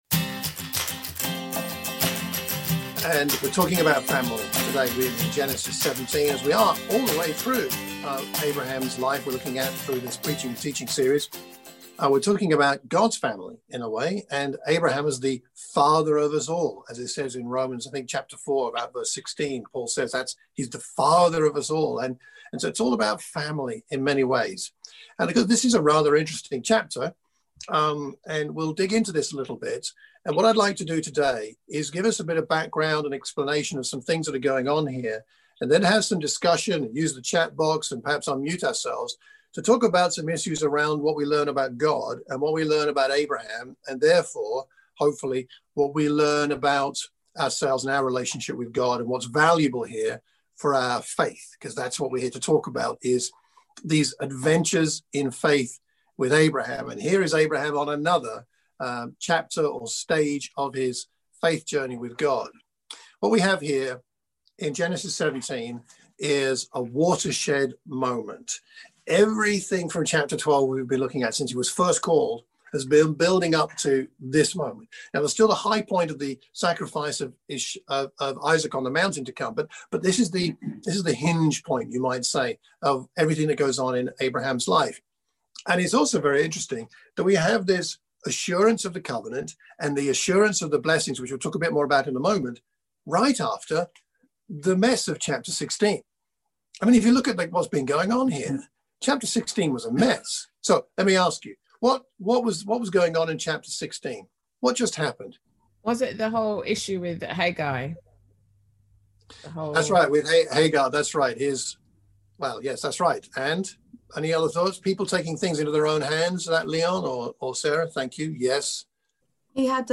A sermon for the Watford church of Christ